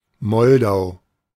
The Vltava (/ˈvʊltəvə, ˈvʌl-/ VU(U)L-tə-və,[1][2][3] Czech: [ˈvl̩tava] ; German: Moldau [ˈmɔldaʊ]
De-Moldau.ogg.mp3